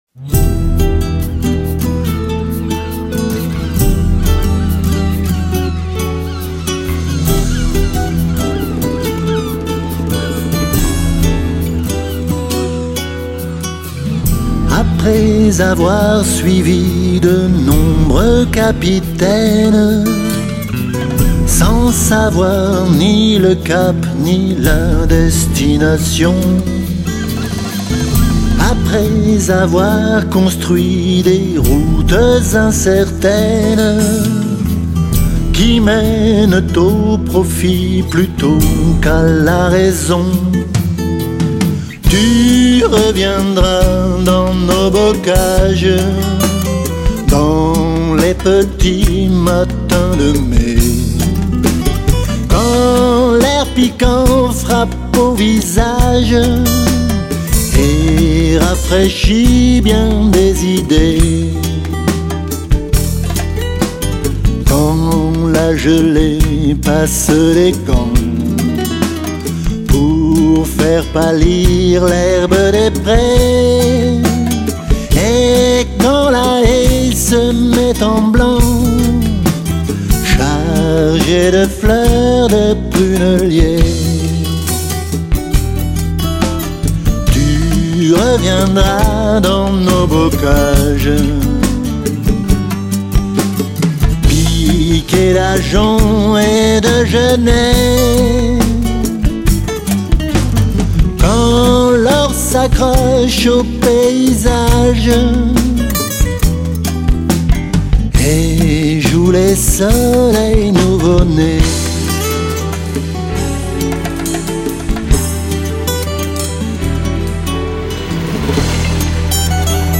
une ambiance plus mélancolique, plus blues, plus bossa